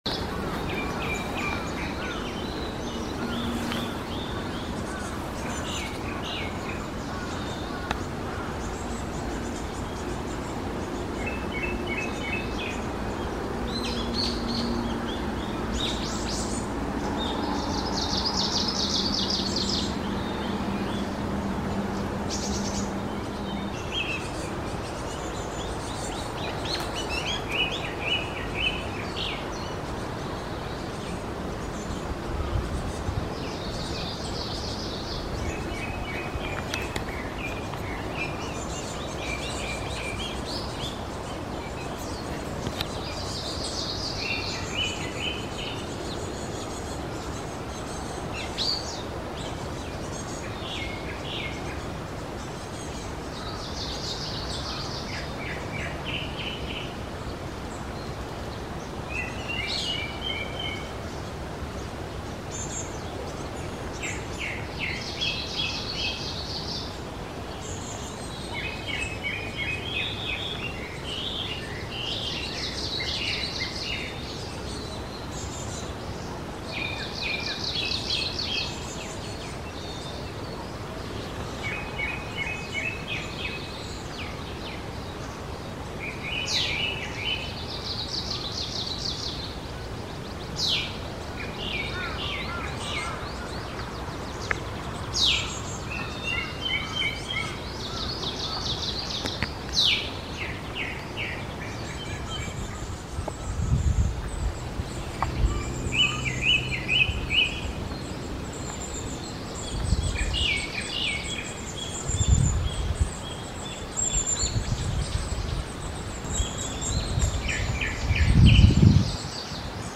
雑木林では、初のオオムシクイの声が聞こえた。
さらにやや離れたところからクロツグミの声が聞こえた。近くで鳴いているオオムシクイの声をかき消すほどの高い声が響いていた。
オオムシクイ、クロツグミの声　→